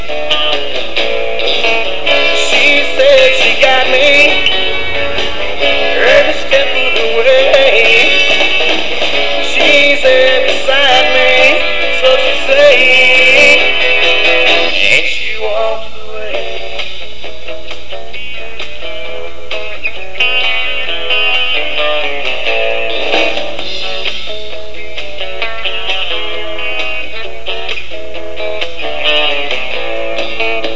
Recording In Nashville